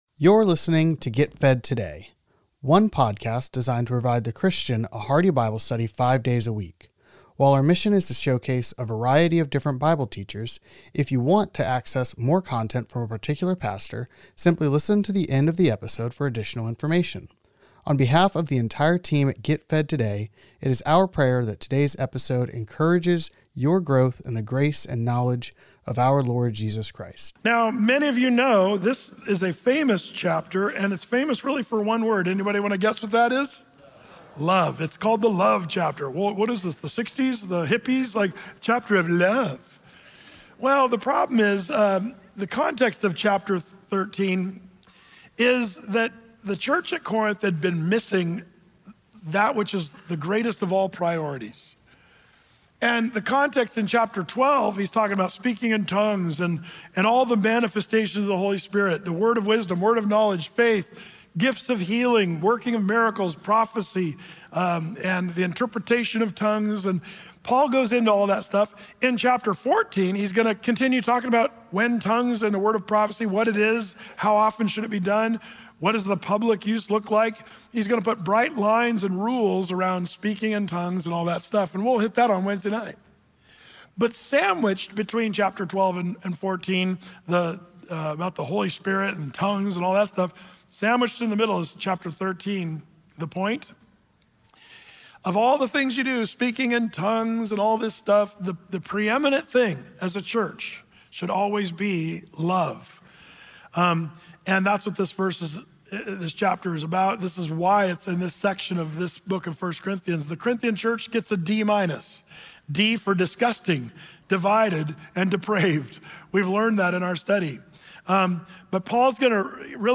Today's sermon is Agape: God’s Definition Of Love